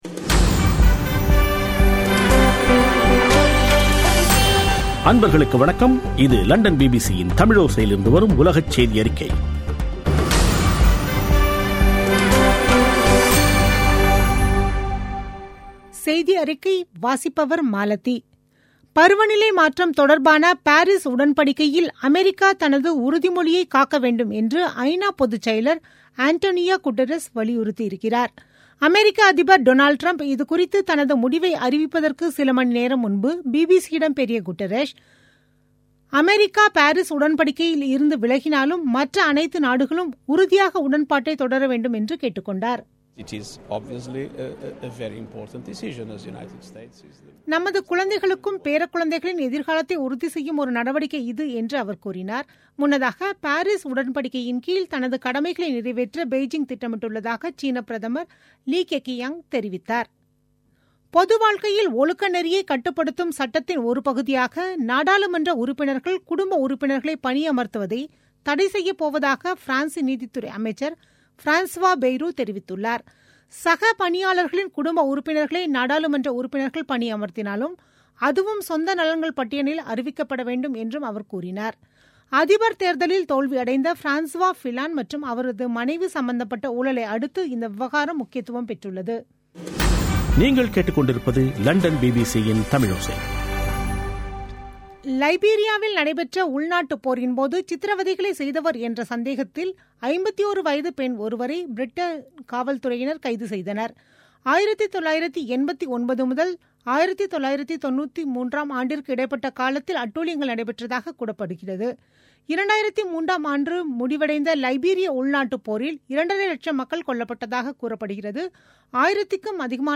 பிபிசி தமிழோசை செய்தியறிக்கை (01/06/2017)